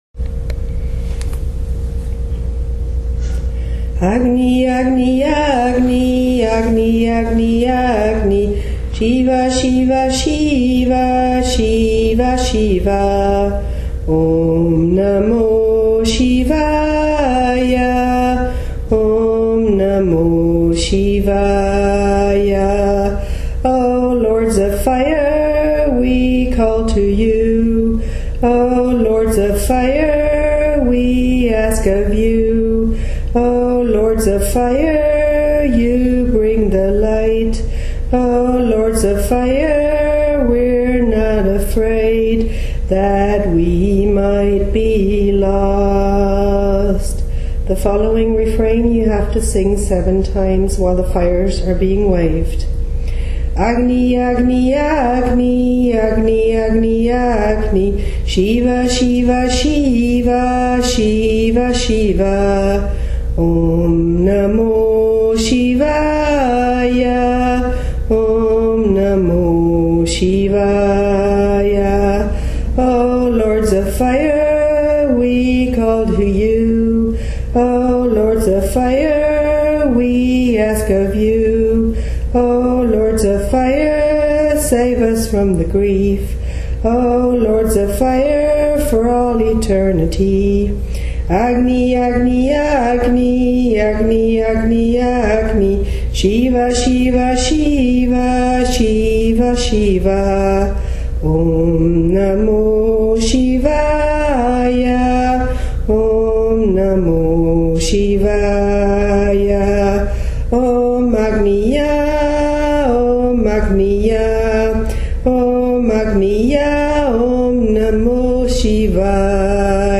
阿格尼火典旋律：